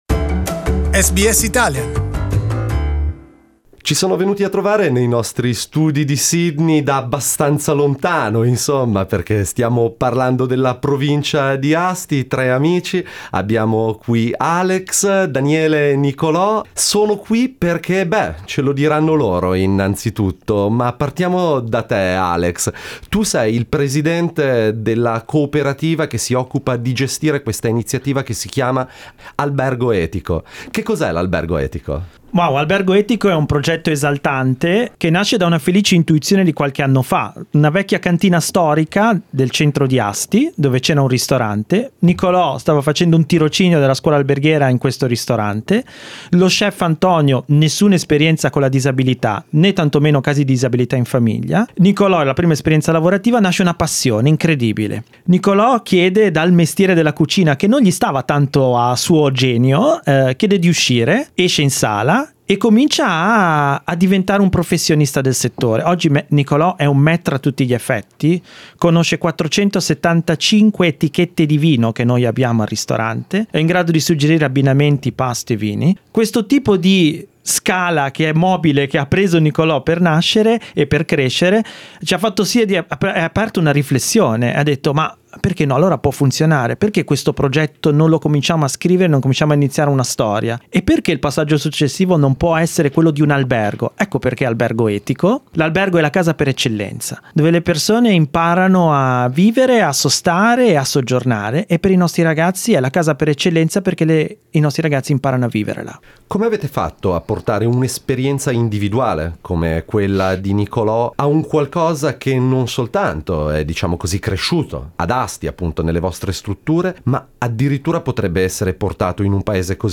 Ascolta SBS Italian tutti i giorni, dalle 8am alle 10am.